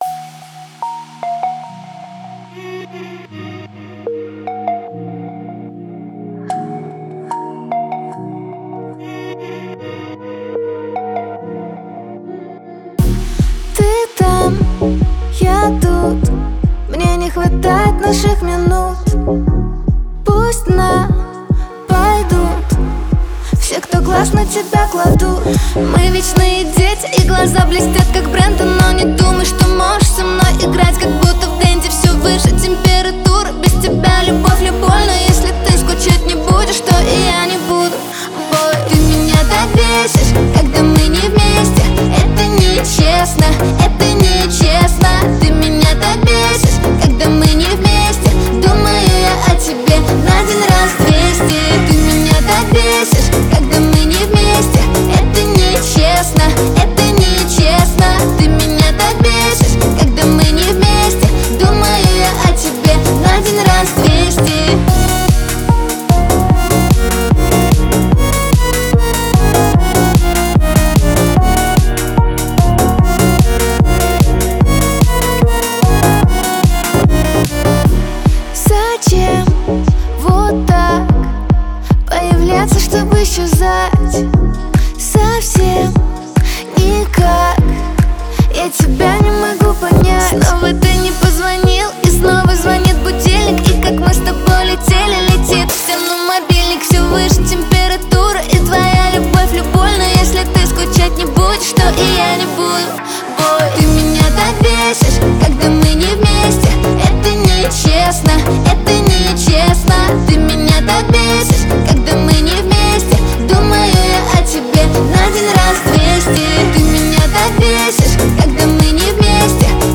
Жанр:Русские новинки / OSTСаундтреки